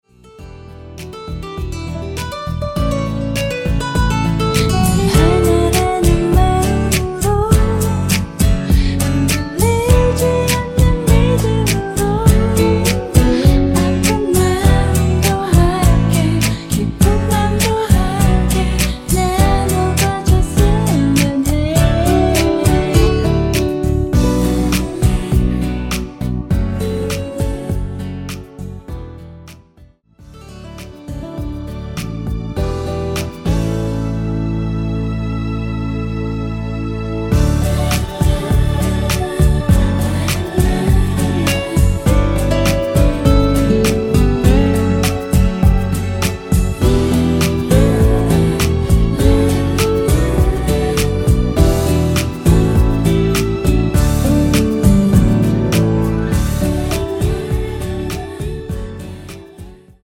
◈ 곡명 옆 (-1)은 반음 내림, (+1)은 반음 올림 입니다.
앞부분30초, 뒷부분30초씩 편집해서 올려 드리고 있습니다.